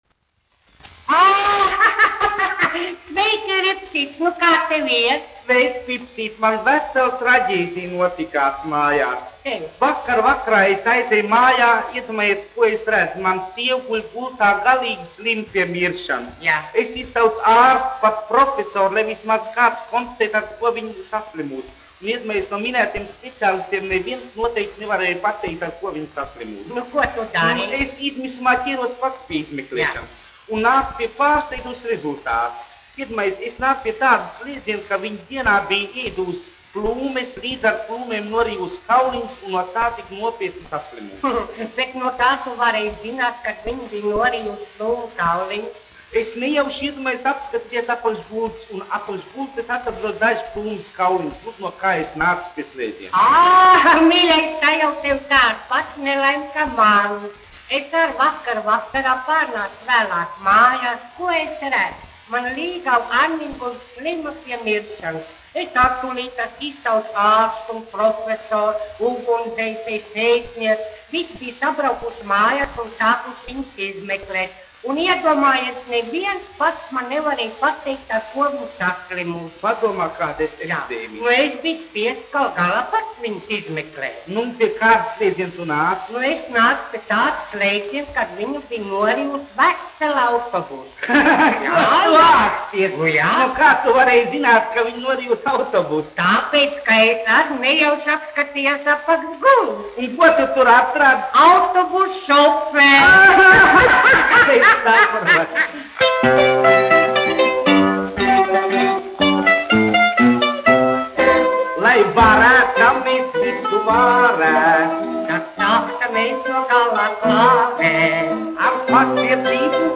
Ripsis un Pipsis, izpildītājs
1 skpl. : analogs, 78 apgr/min, mono ; 25 cm
Humoristiskās dziesmas
Latvijas vēsturiskie šellaka skaņuplašu ieraksti (Kolekcija)